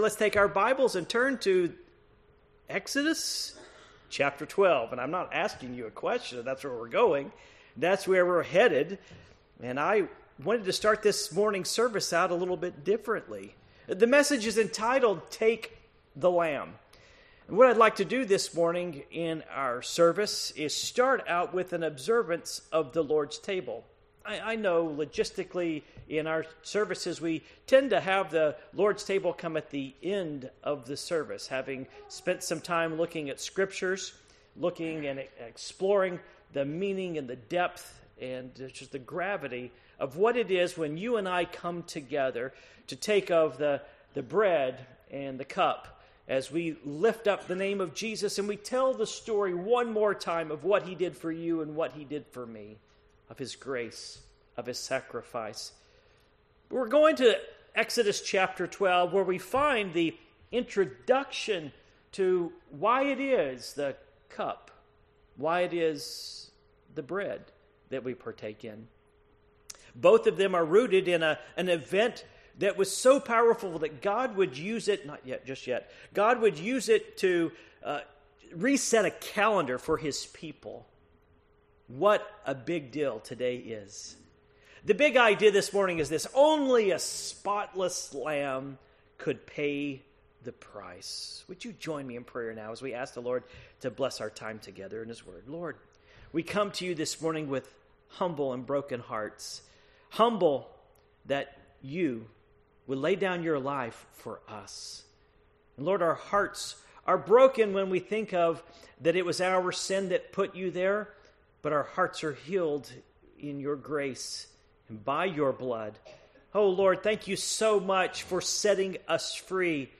Passage: Exodus 12:1-12 Service Type: Morning Worship